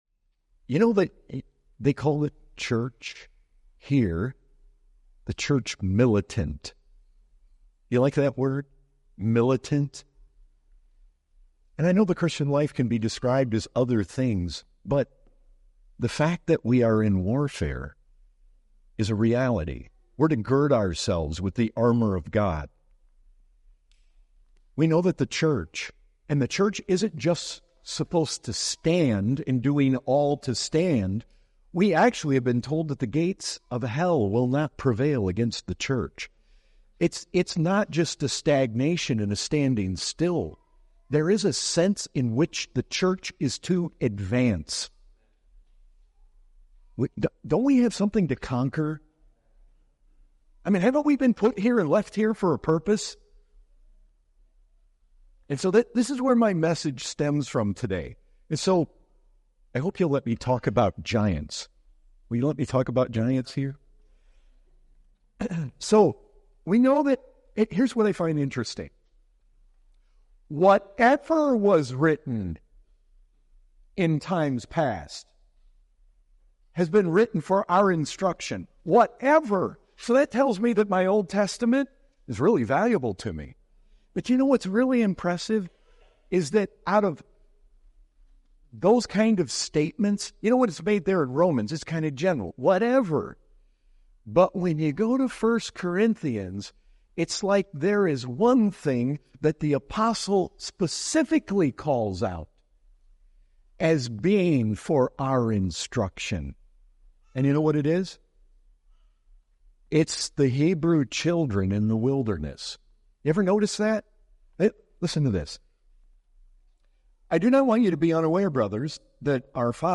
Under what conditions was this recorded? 2023 Fellowship Conference UK | We need to press forward with the faith of Joshua and Caleb, and though grasshoppers we are, let us go and conquer the giants in the land.